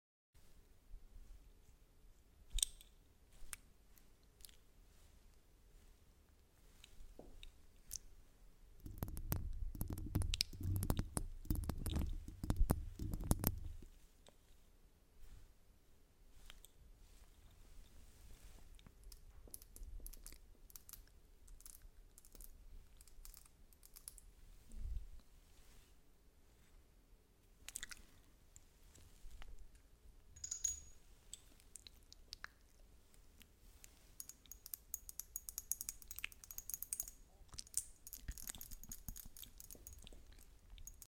Yzma villainess tries ASMR nails sound effects free download